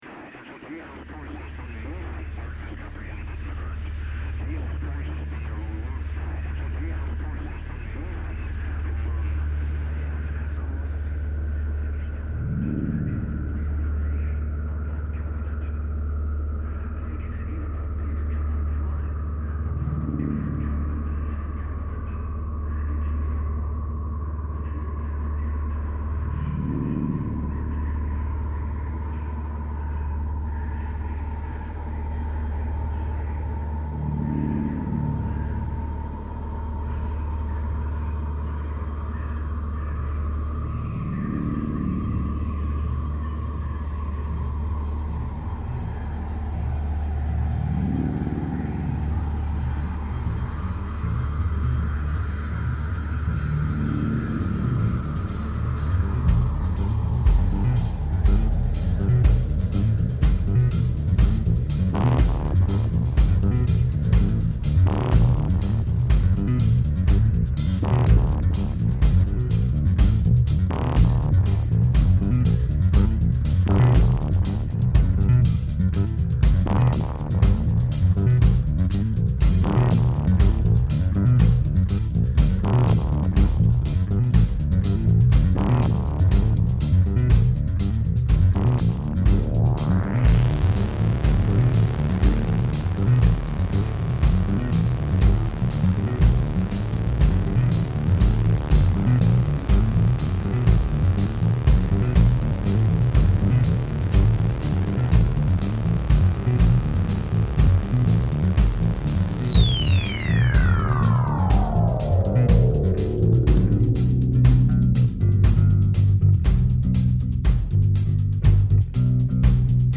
An experimental improvisation trio